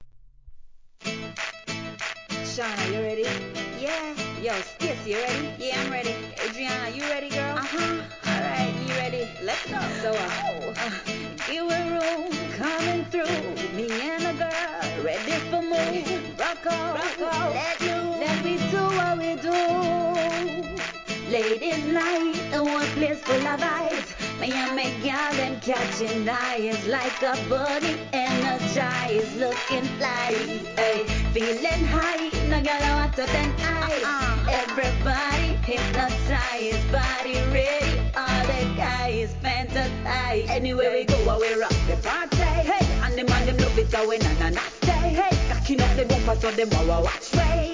REGGAE
情熱ギターの好JUGGLIN!!